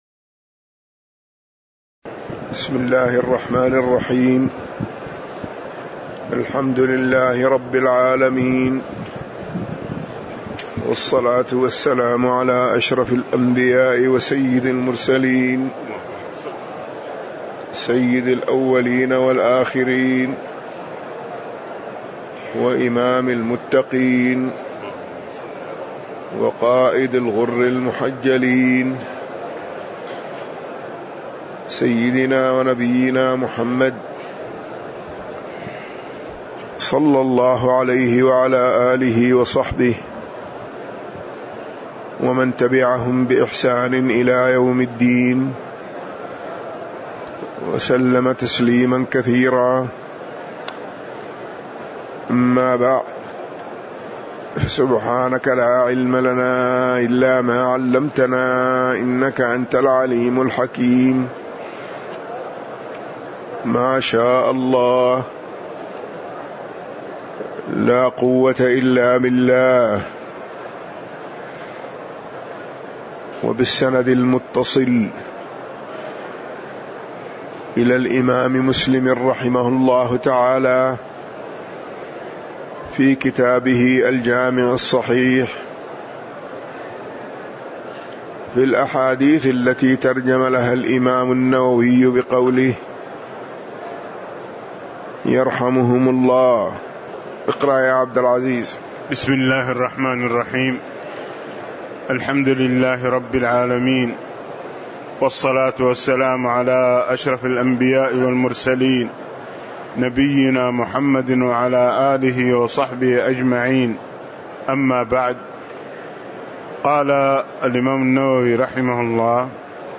تاريخ النشر ٢ محرم ١٤٣٨ هـ المكان: المسجد النبوي الشيخ